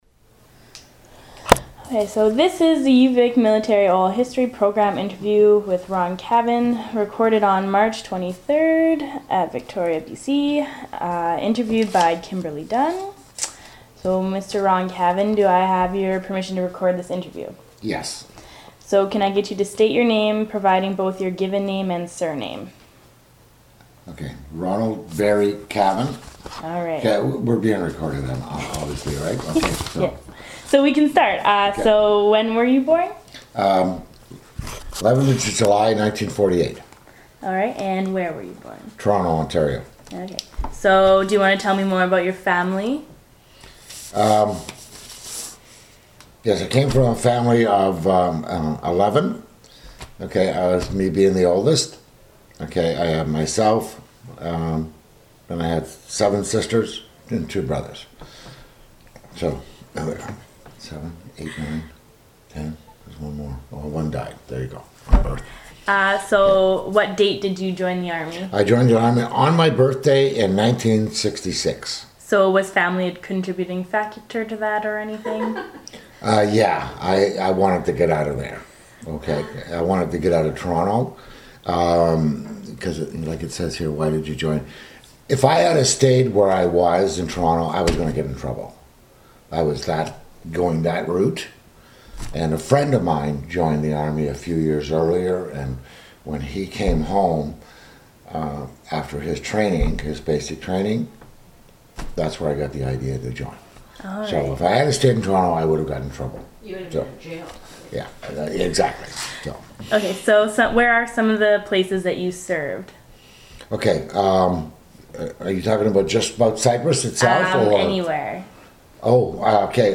Interview took place on March 23, 2013.